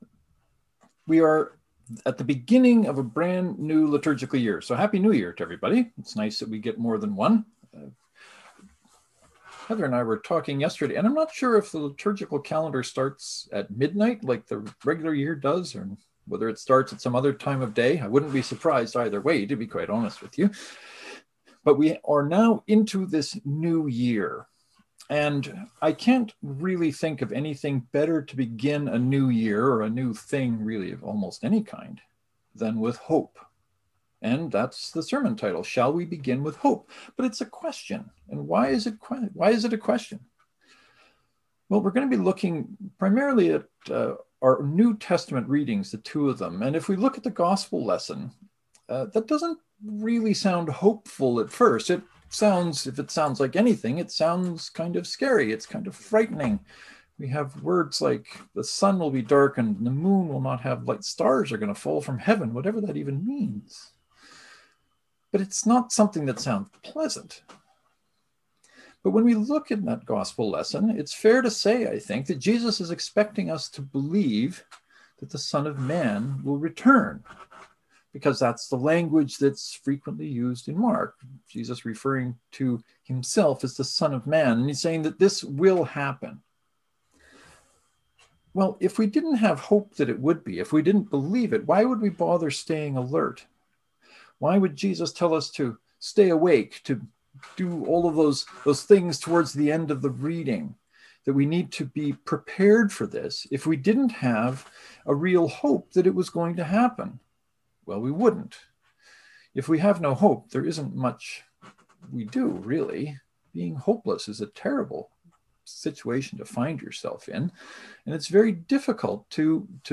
St. Paul gives us some reasons for hope in 1 Corinthians and that is where we spent most of our time this Sunday. I hope (no pun intended) you find reasons to and for hope in this sermon.
Knox and St. Mark’s Presbyterian joint service (to download, right click and select “Save Link As .